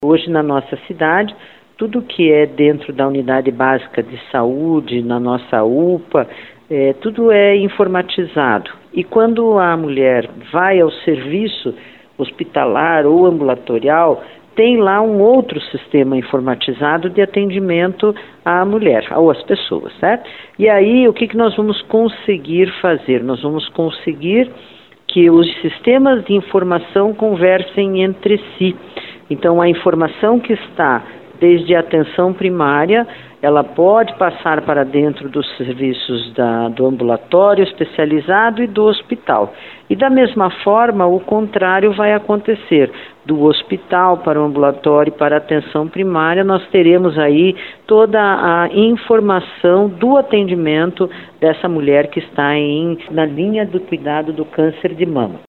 A secretária municipal de Saúde, Beatriz Battistella, explica que é um projeto-piloto, que vai começar a ser implantado com pacientes da regional Cajuru, com potencial para ser expandido para toda a cidade.